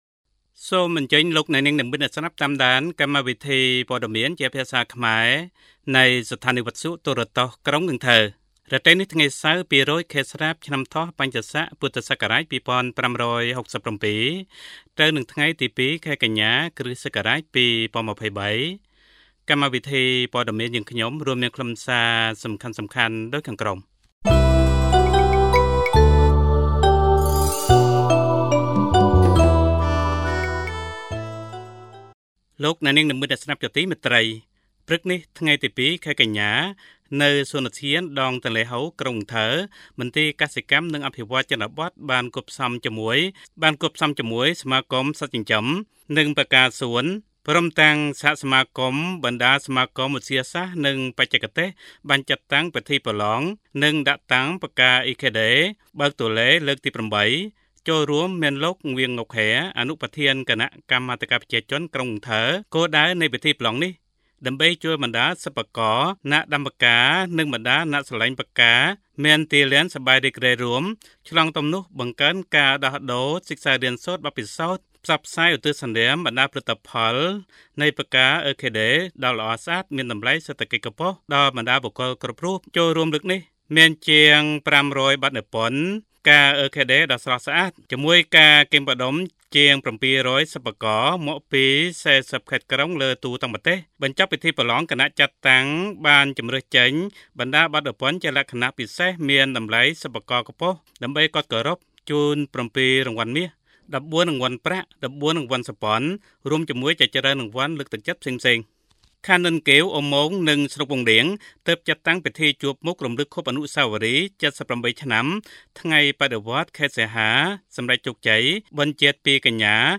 Bản tin tiếng Khmer tối 2/9/2023